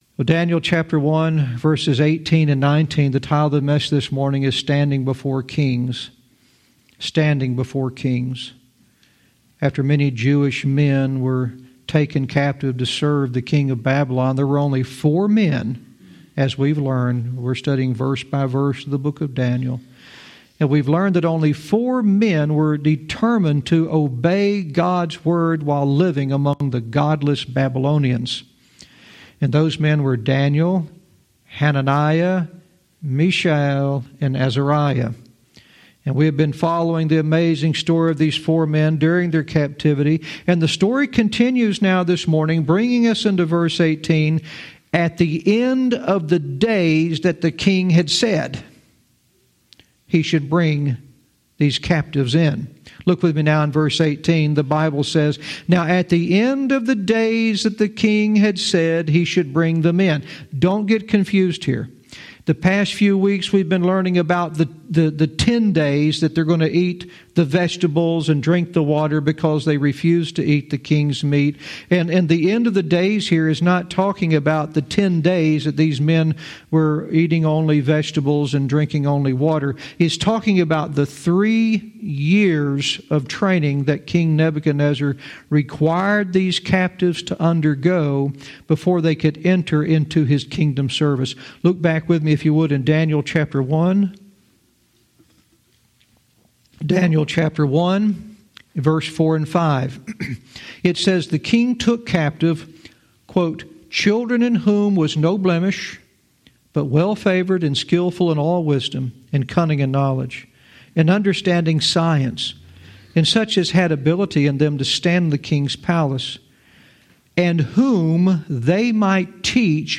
Verse by verse teaching - Daniel 1:18-19 "Standing Before Kings"